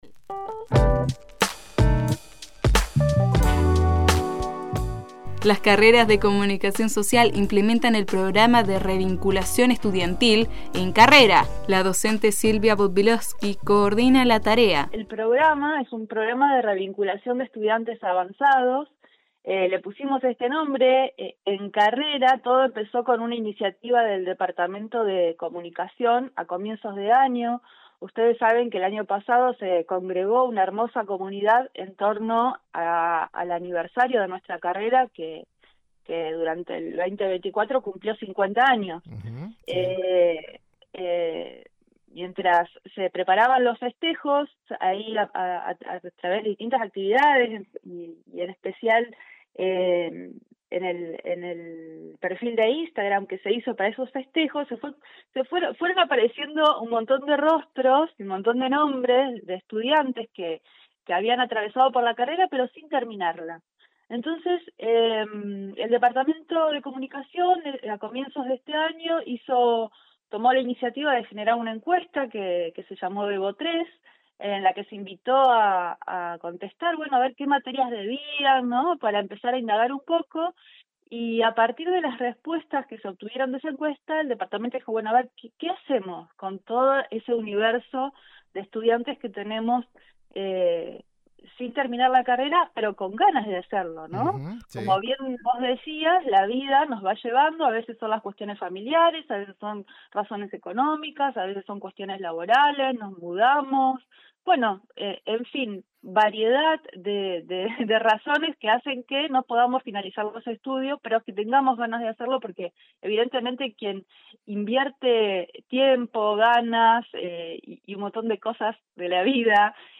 En diálogo con Horizonte Universitario